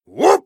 whoop